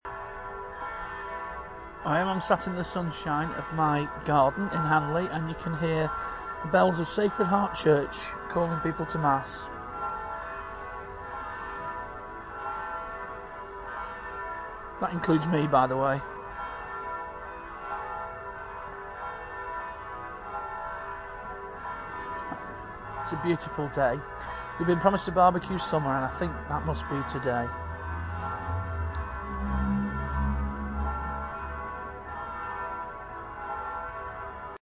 The bells of Sacred Heart Hanley
53284-the-bells-of-sacred-heart-hanley.mp3